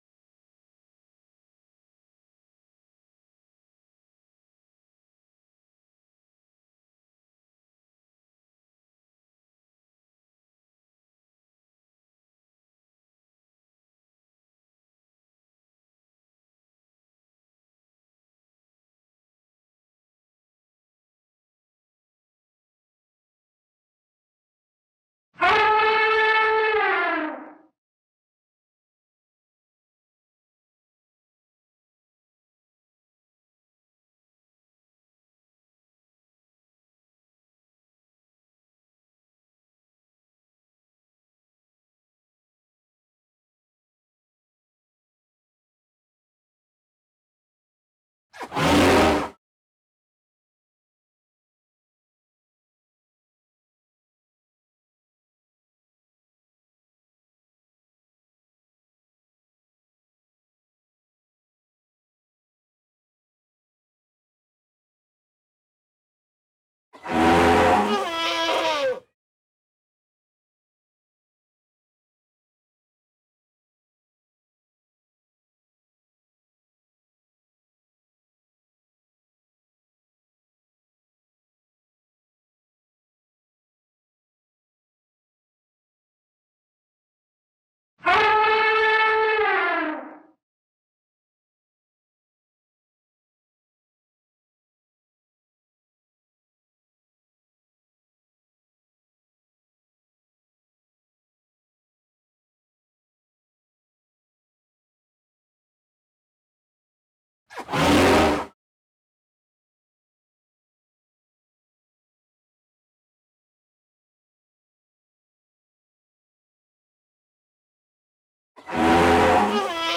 SFX_Intro_Elephant_01.ogg